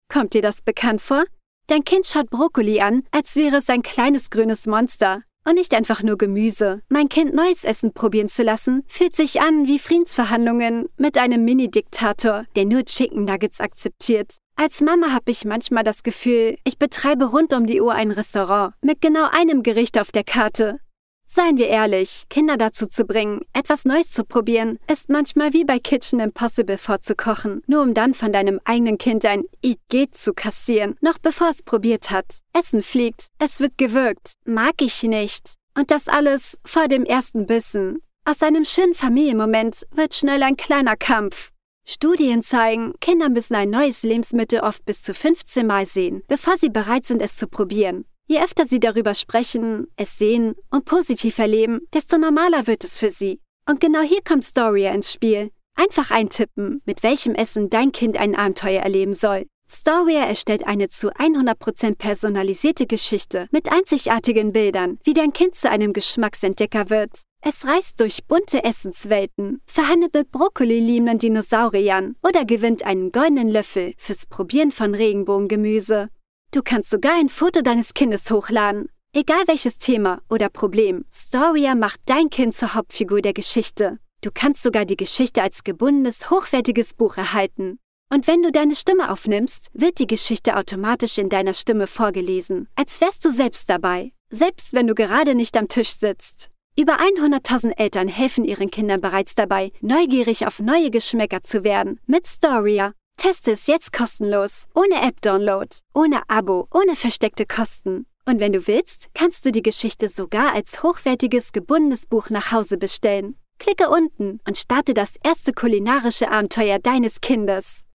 warm, friendly, mid timbre, very wide voice range: from dynamic teenage voice to distinguished elderly lady. particularly well in voice over, story telling, e-learnings and dynamic commercials....